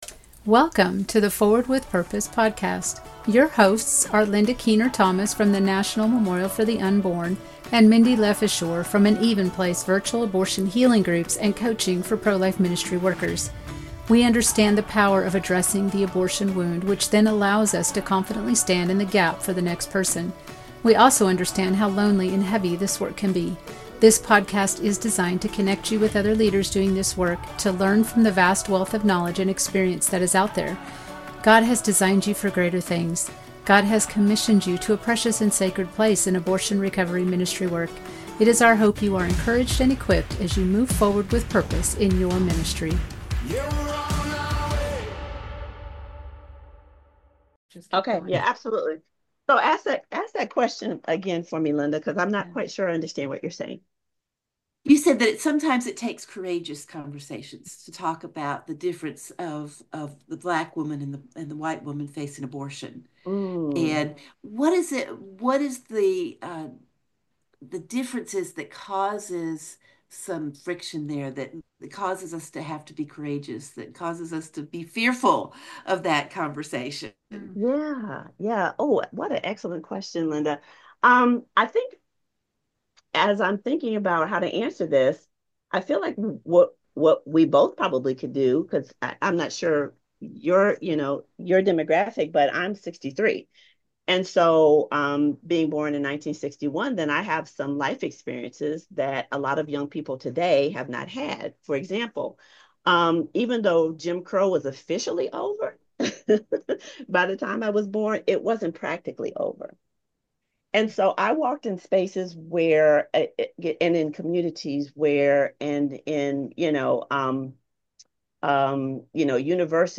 S3E2 Helping the woman who wants healing but justifies her abortion panel discussion – Forward With Purpose – Podcast